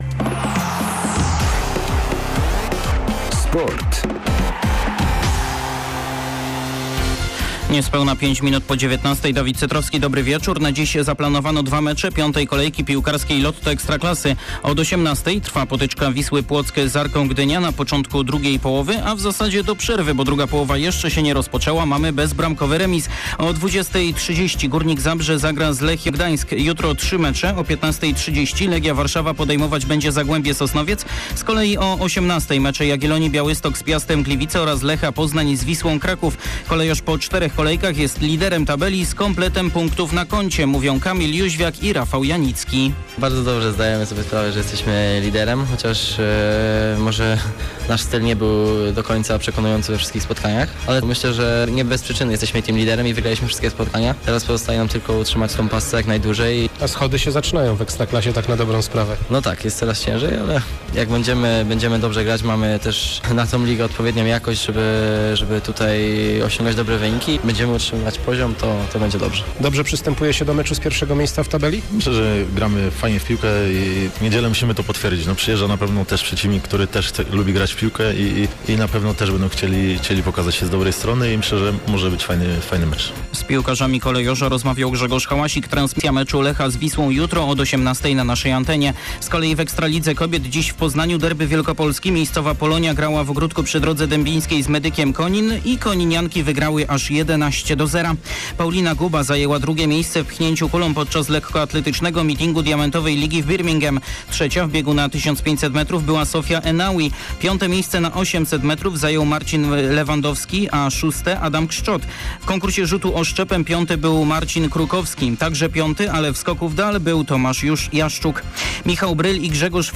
18.08 serwis sportowy godz. 19:05